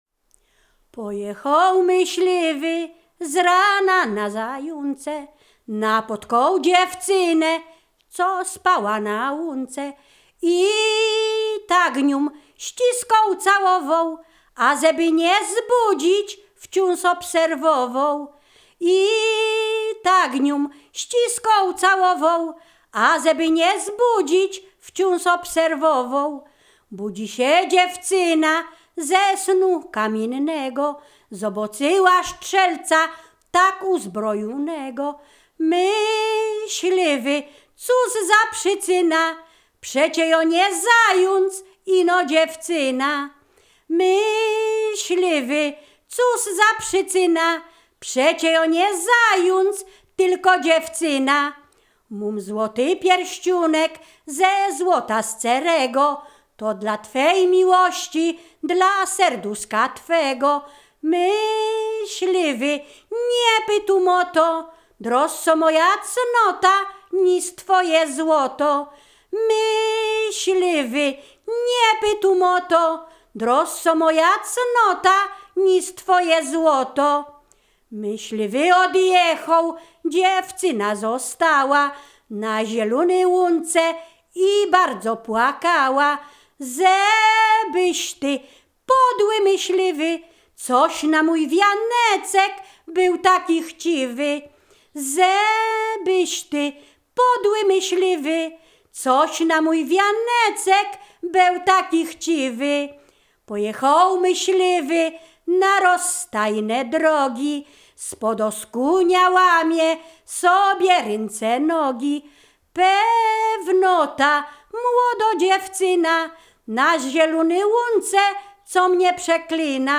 ballady liryczne miłosne